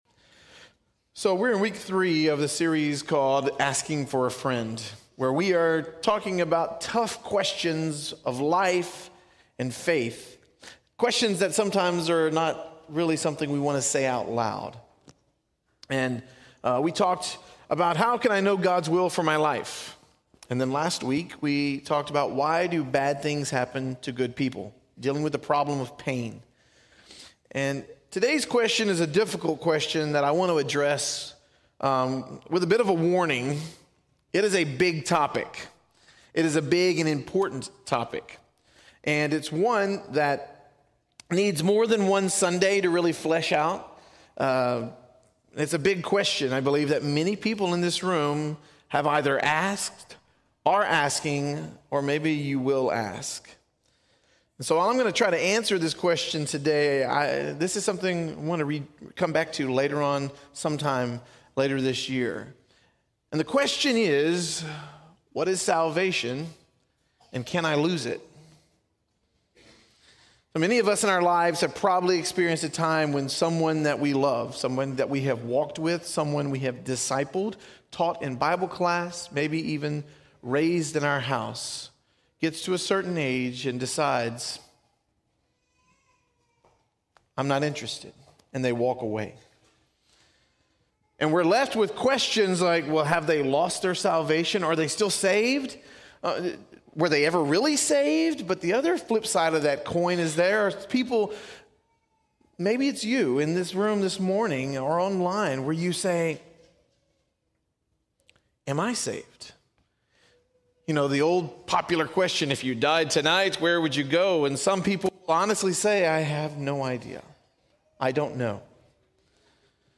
In this sermon, we will explore what salvation is and how we can be assured that our salvation is secure - not because of anything we do, but solely on who God is and what Jesus has done.